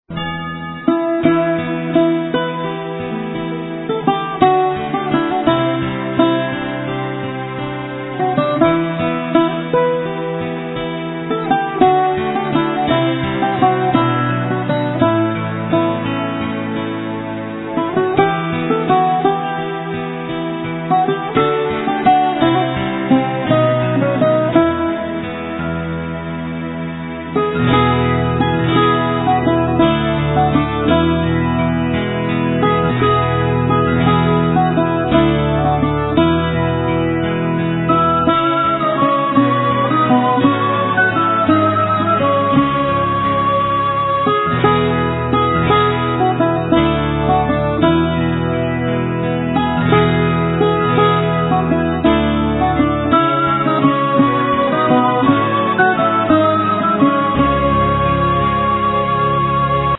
Sevi, Doudouk
Violin, Viola
Acoustic bass
Drams, Percussions
Bouzouki
Piano, Orchestration, Programming
Narration